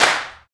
87 BIG CLP-L.wav